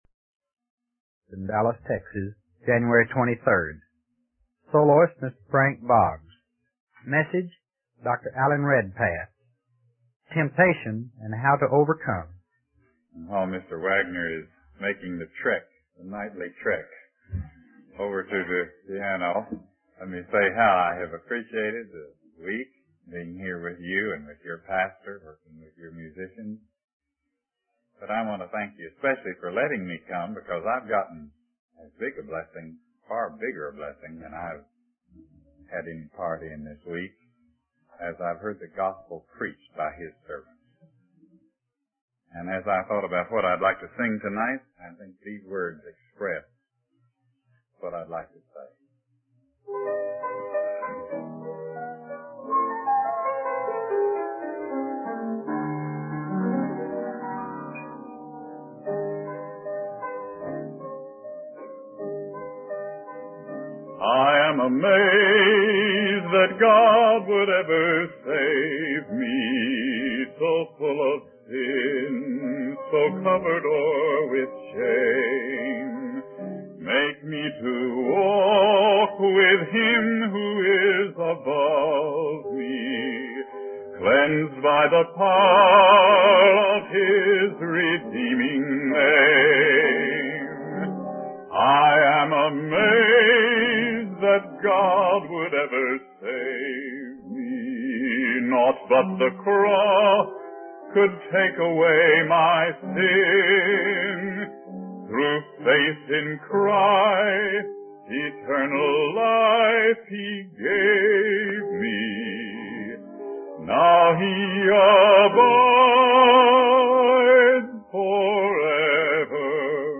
In this sermon, the preacher emphasizes the importance of keeping our focus on Jesus and committing ourselves to Him. He uses the analogy of Peter walking on water to illustrate how we can easily be distracted by the waves and wind of life. The preacher also highlights the need for faith and surrender to the Holy Spirit in order to live a victorious life.